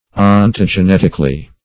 -- On`to*ge*net"ic*al*ly , adv.